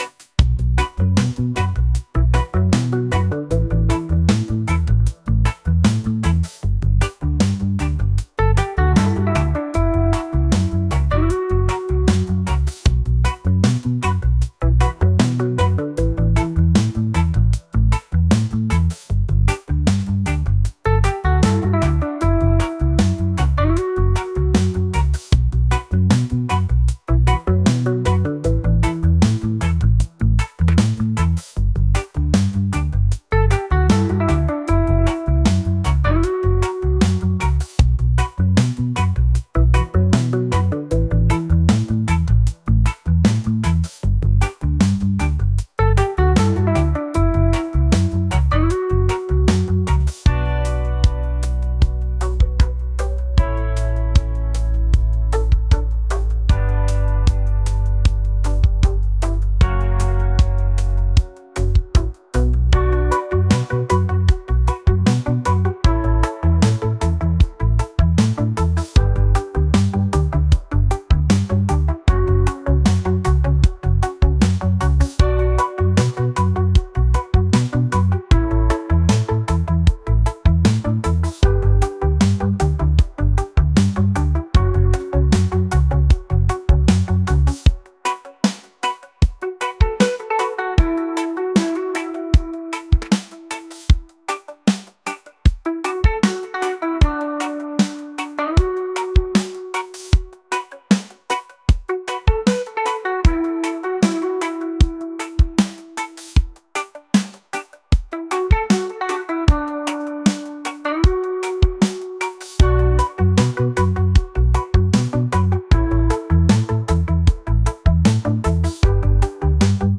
reggae | soul & rnb | lounge